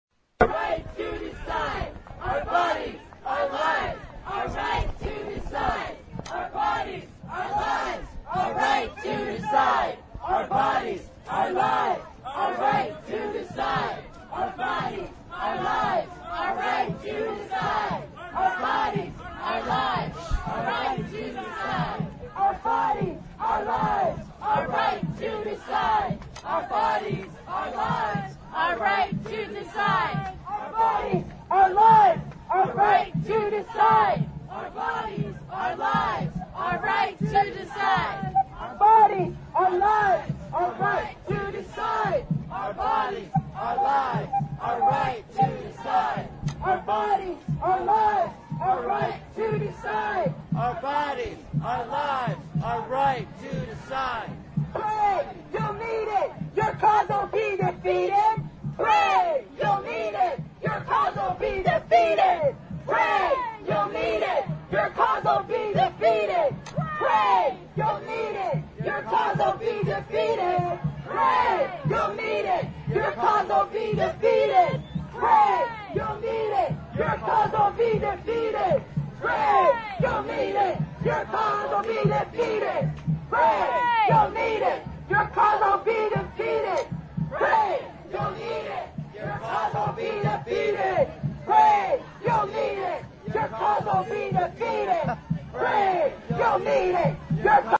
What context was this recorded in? Hear audio from the rally and chants/music from the march, starting with 2 minutes of chants from the march.